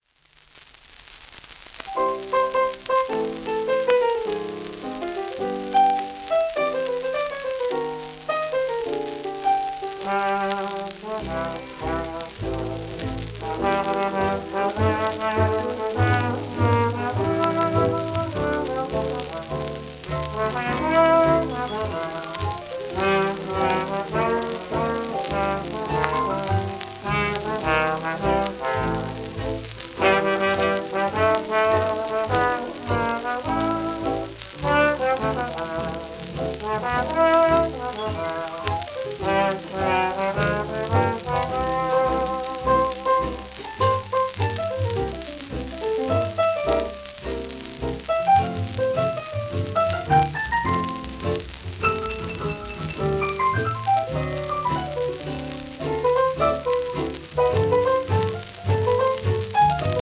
1935年録音